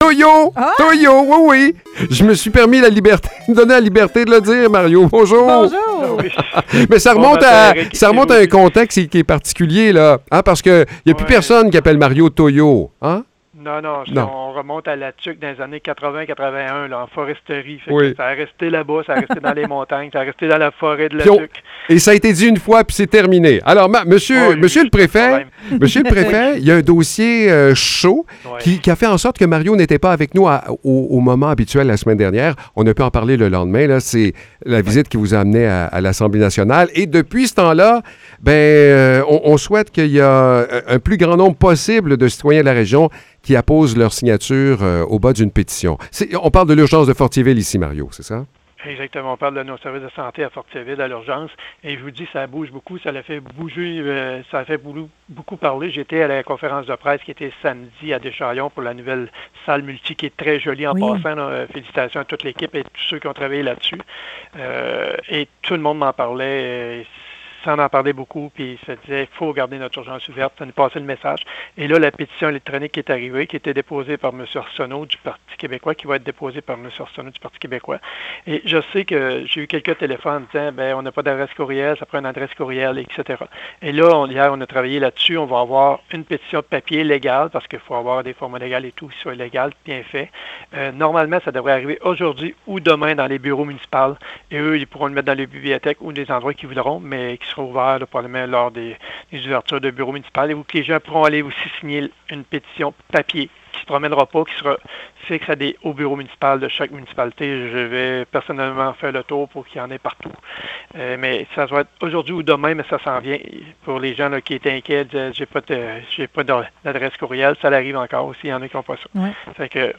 Échange avec Mario Lyonnais